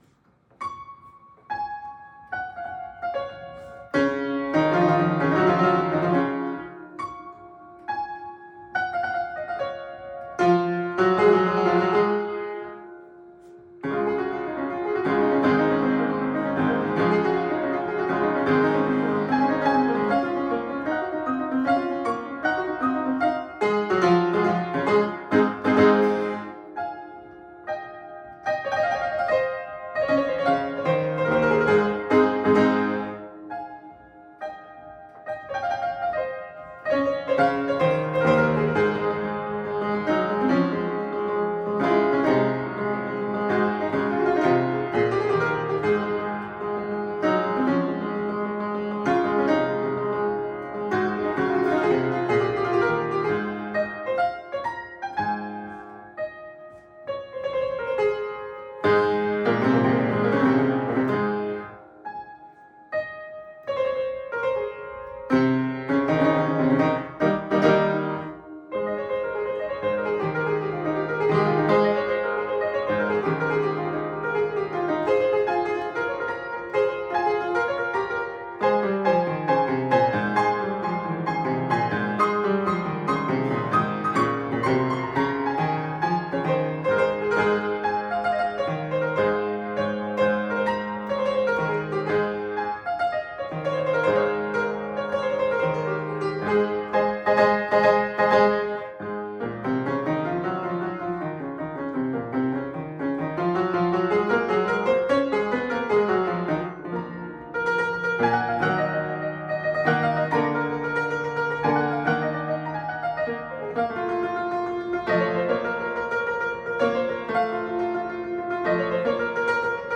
This transcription of the overture for piano solo was copied after 1805, when the previous items in the manuscript book were published; based on the publication year of the likeliest source for her copy, however, it was probably some years before 1814, when she attended the opera in London.
on a Graf replica pianoforte